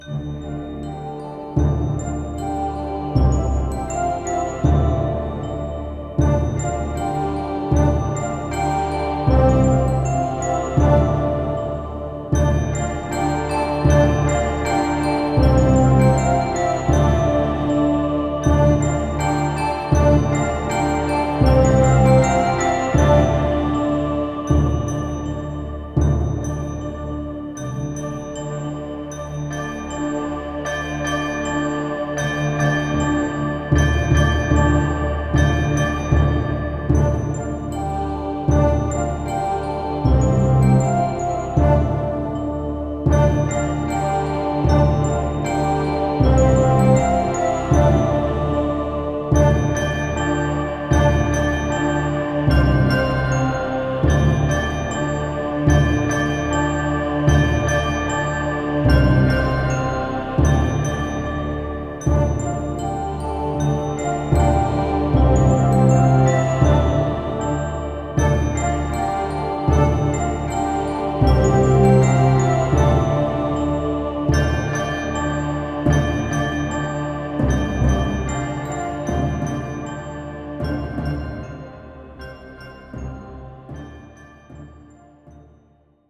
Epic Soundtrack.